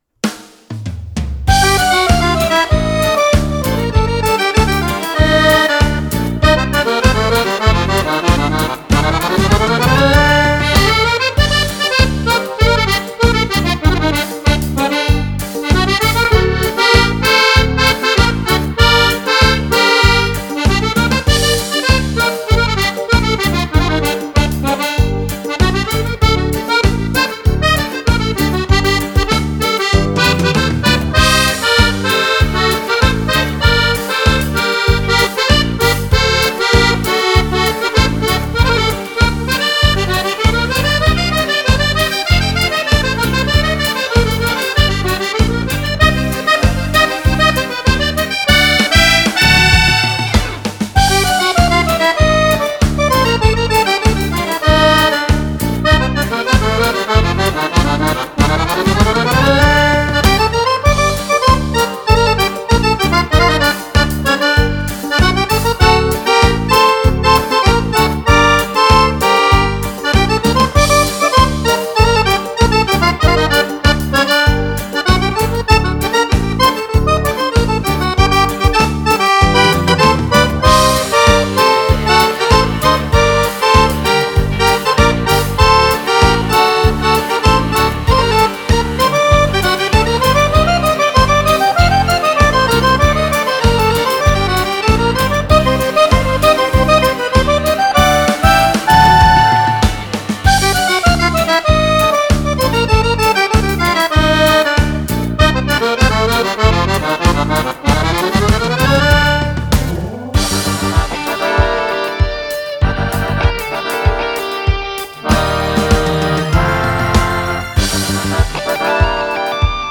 Cumbia per Fisarmonica